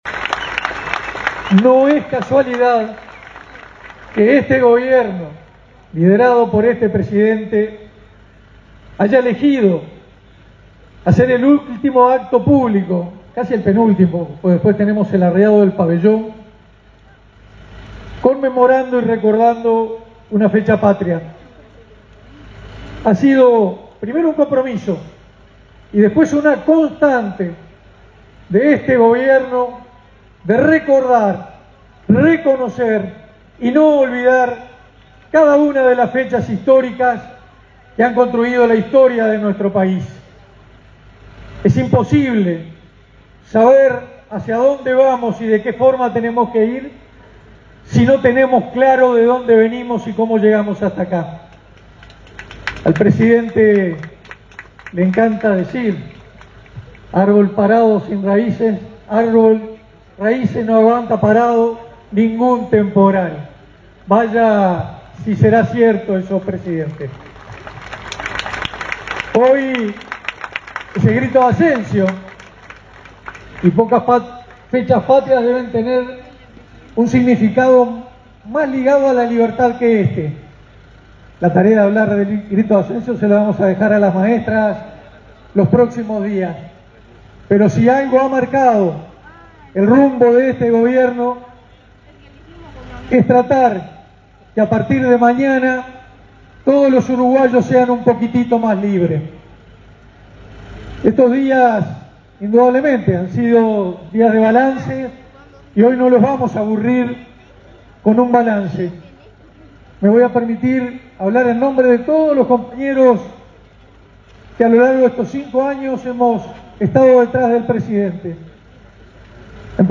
Palabras del ministro de Defensa Nacional, Armando Castaingdebat
Palabras del ministro de Defensa Nacional, Armando Castaingdebat 28/02/2025 Compartir Facebook X Copiar enlace WhatsApp LinkedIn El ministro de Defensa Nacional, Armando Castaingdebat, fue el orador central en el acto por el aniversario del Grito de Asencio, en el departamento de Soriano.